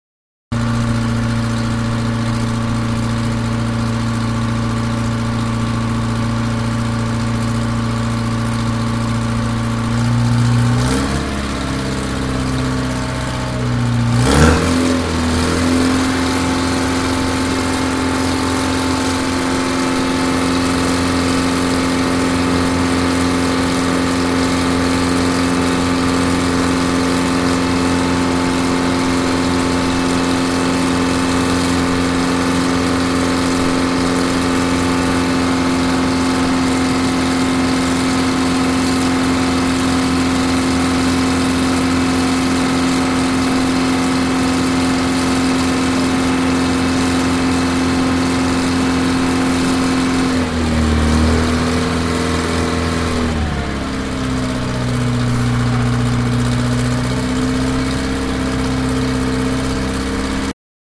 На этой странице представлены звуки башенного крана — мощные и ритмичные шумы строительной техники.
Гул мотора дизельного крана